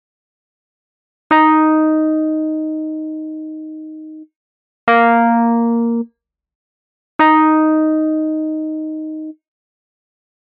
Key written in: E♭ Major
Each recording below is single part only.